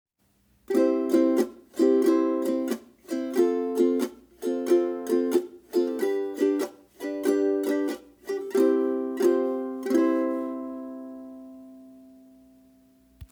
Die Cascha Carbon Ukulele überzeugt durch ihre Einzigartigkeit und den herausragenden Klang.
Die präzisen Stimmmechaniken und langlebigen, stimmfesten Fluorcarbon-Saiten machen diese Ukulele zu einem sehr stimmstabilen Instrument mit heller und klarer Klangfarbe.